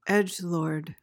PRONUNCIATION: (EJ-lord) MEANING: noun: A person who affects an edgy persona by saying or doing provocative or offensive things, chiefly to attract attention.